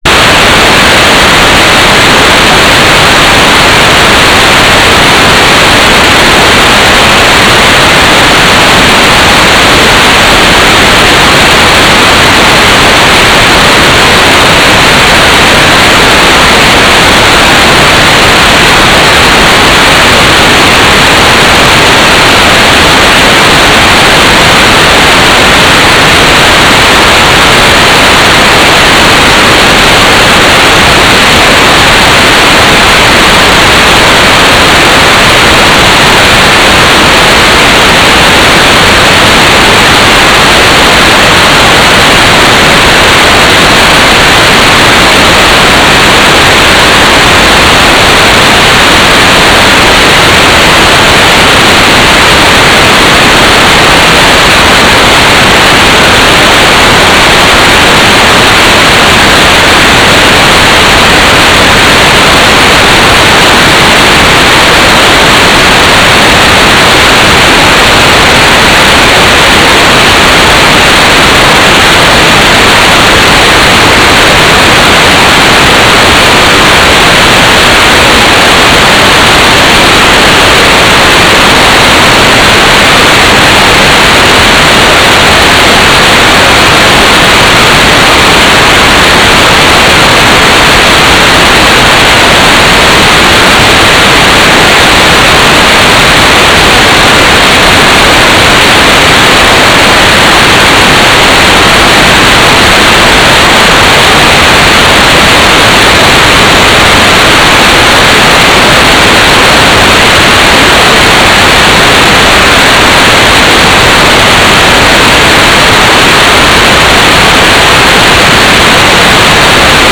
"transmitter_description": "4k8 GMSK TLM",
"transmitter_mode": "GMSK",